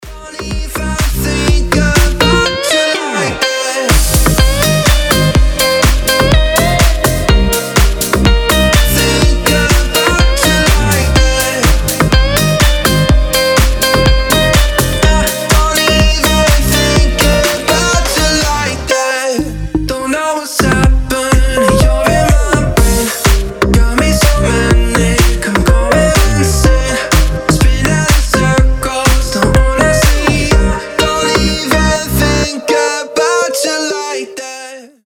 громкие
deep house
мелодичные
Brazilian bass